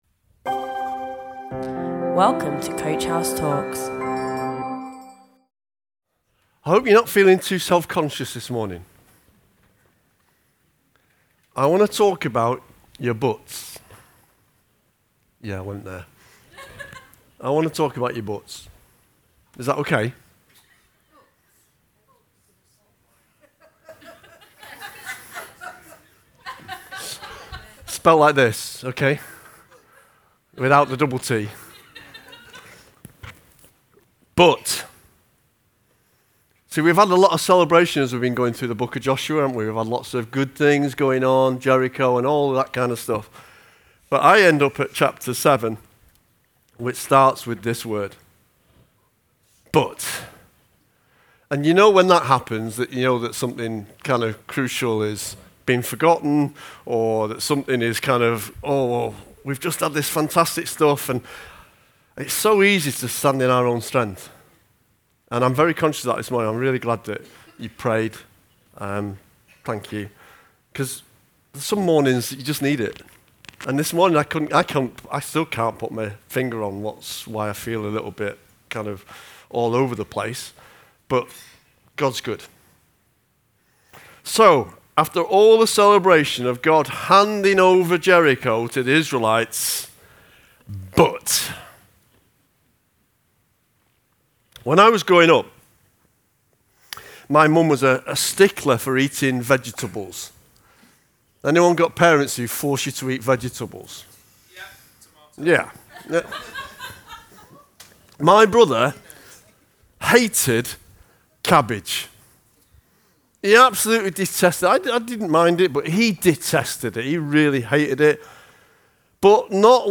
COACH-HOUSE-CHURCH.Sunday142.mp3